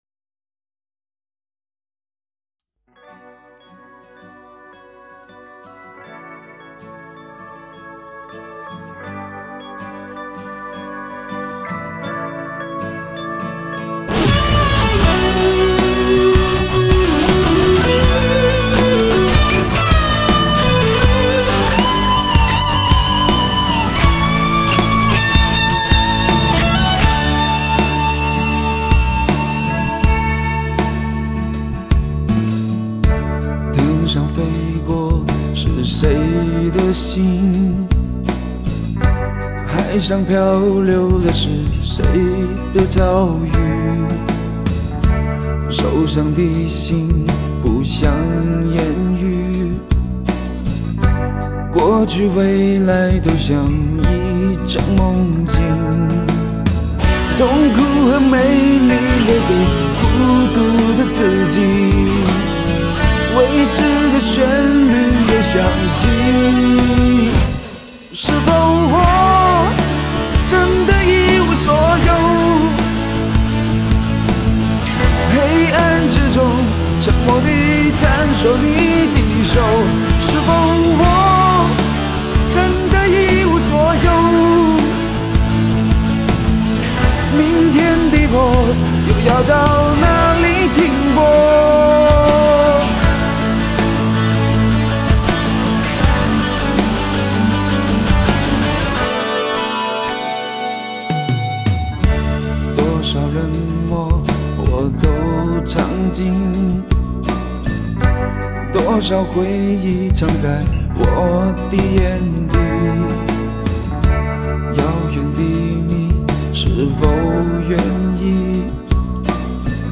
令人心醉的情歌
轻松优美的旋律